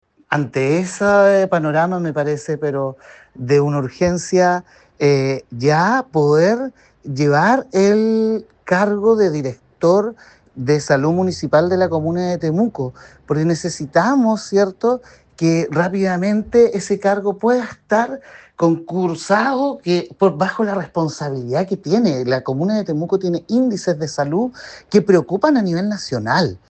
Desde el concejo municipal, el edil del PS, Esteban Barriga, reveló la urgencia de iniciar un concurso de alta dirección pública para designar a un nuevo encargado de la repartición. Esto, indicando que la comuna tiene importantes necesidades en esta materia.